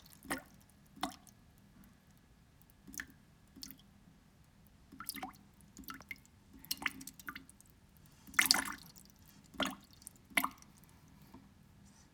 Water drips into bowl.wav